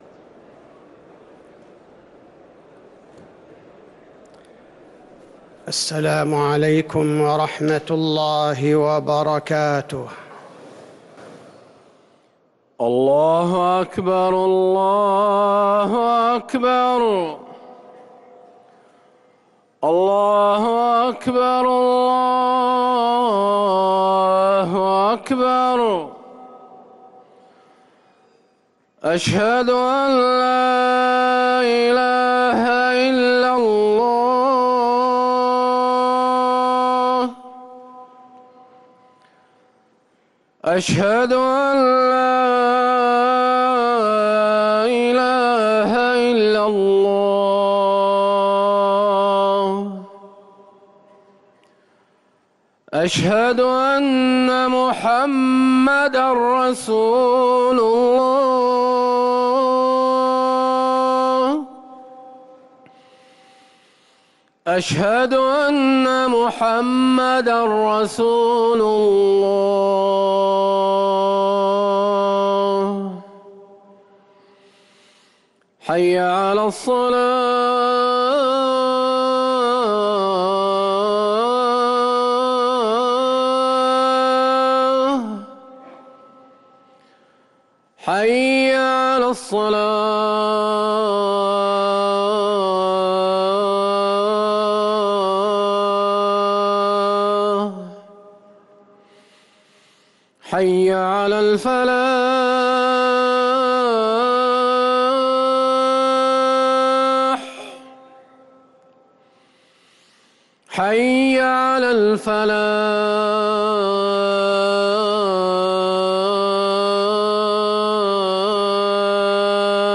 أذان الجمعة الثاني للمؤذن